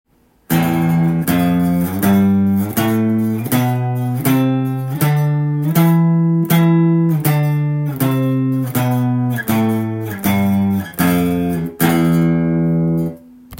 ６弦と５弦をルート音にして横にスライドさせながら弾く奏法です。
「6弦オクターブ」
６弦を使用するオクターブ奏法では６弦に人差し指を置き